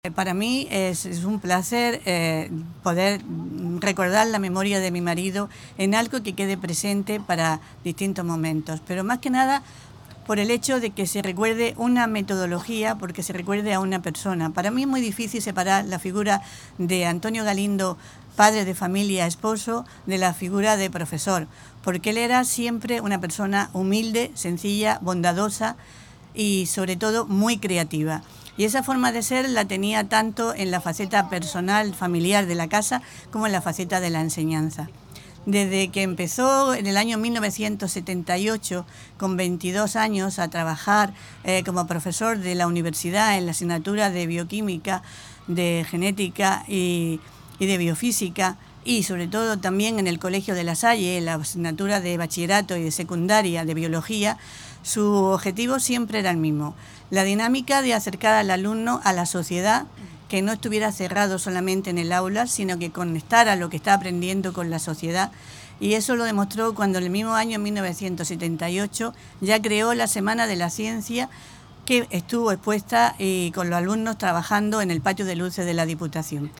El Ayuntamiento de la capital ha celebrado este jueves un acto institucional para inaugurar este espacio verde ubicado entre las calles Abogado de Oficio y carretera de Sierra Alhamilla, junto a la calle La Marina, en el barrio de Ciudad Jardín, que da nombre a “un almeriense ejemplar”.